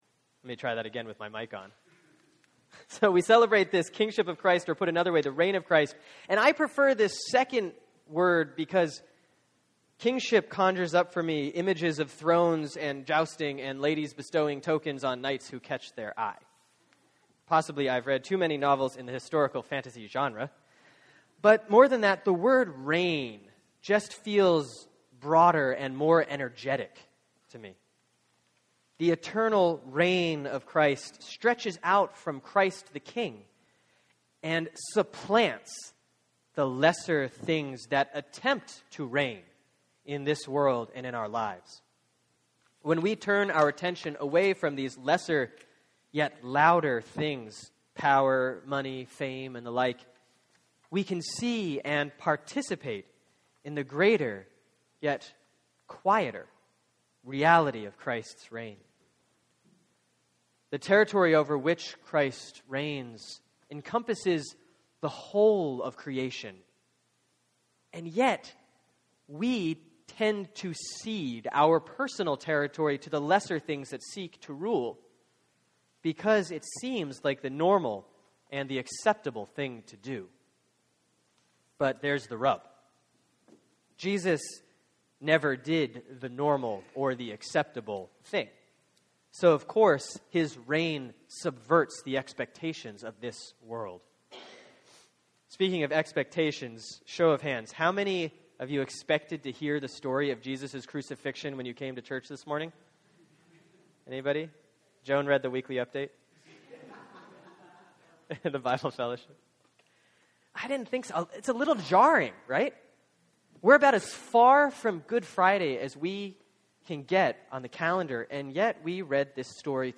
(Sermon for Sunday, November 24, 2013 || Christ the King Year C || Luke 23:33-46)